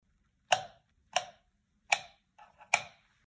Звуки выключателя
Проверка света выключателем